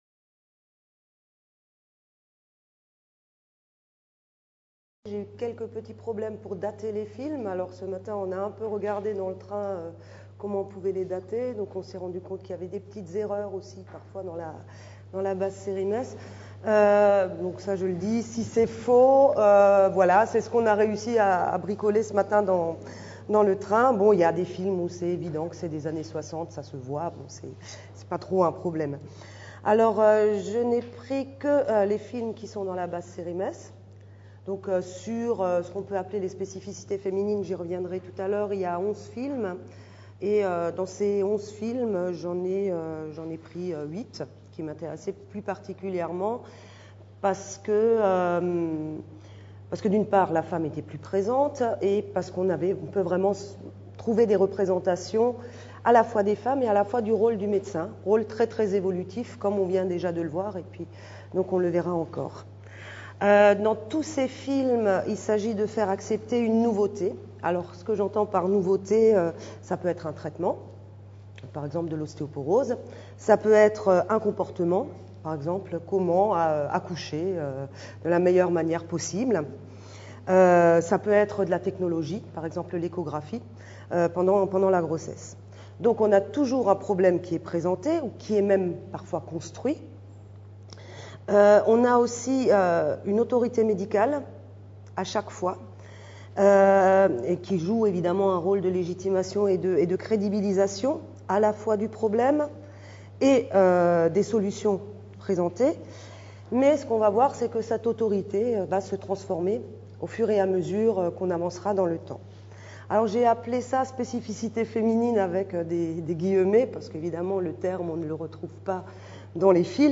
Intervention
MEDFILM Journée d’étude n°2 21 septembre 2012 à l'Université Paris Diderot Grands Moulins